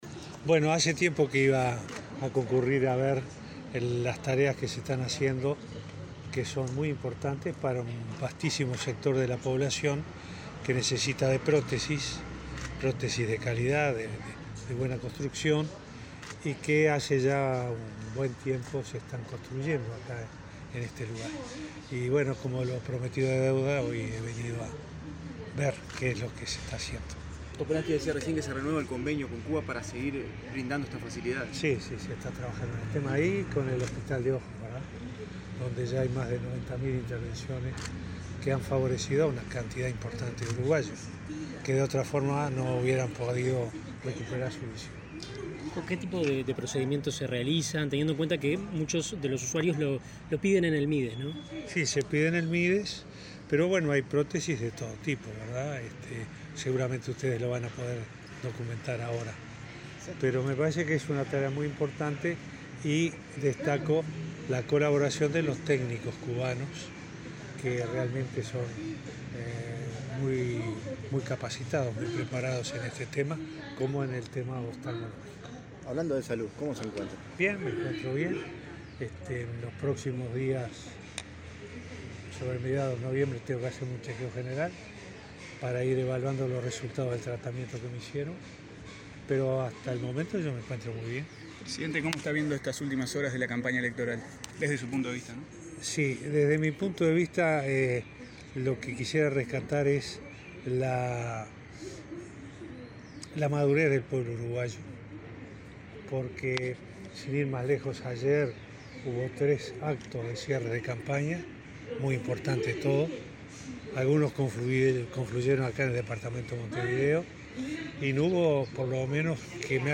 En diálogo con la prensa, habló de diversos temas, dijo que Uruguay es una democracia consolidada, se refirió a la campaña electoral y a la situación de América Latina.